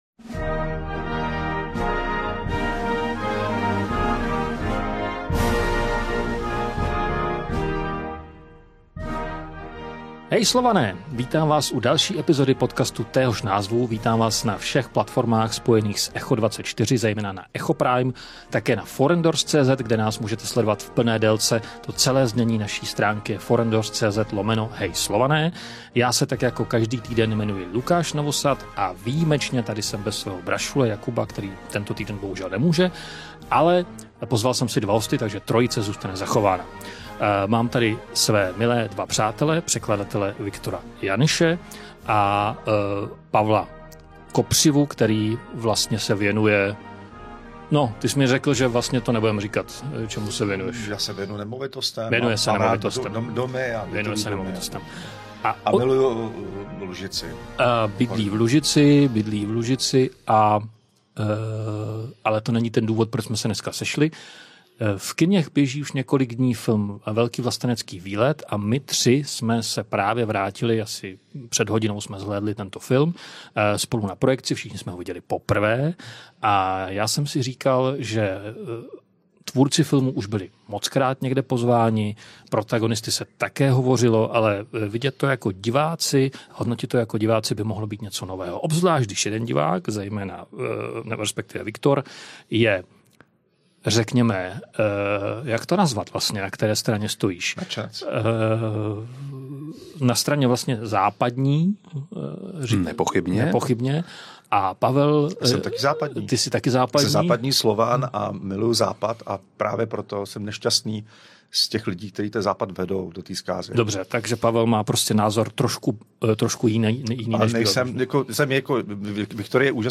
Jeden se označuje za dezoláta, druhý rozhodně nikoli. A třetí usiluje jejich rozhovor moderovat.